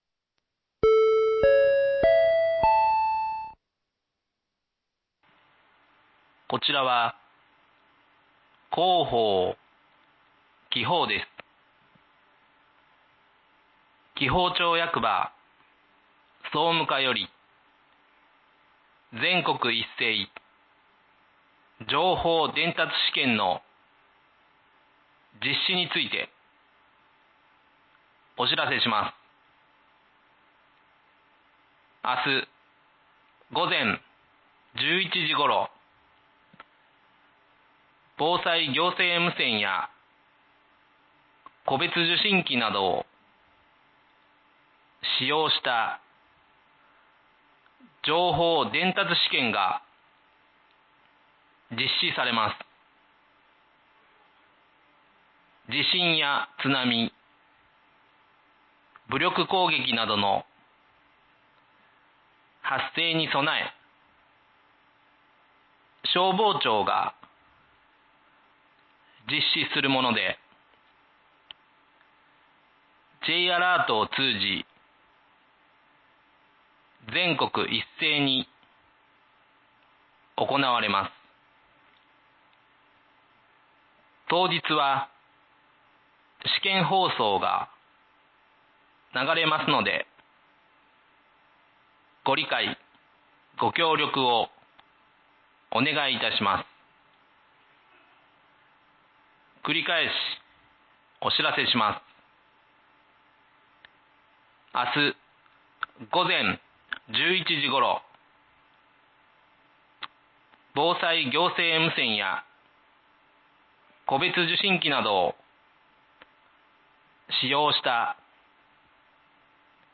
明日、午前１１時頃、防災行政無線や戸別受信機などを使用した情報伝達試験が実施されます。 地震や津波、武力攻撃などの発生に備え、消防庁が実施するもので、Jアラートを通じ、全国一斉に行われます。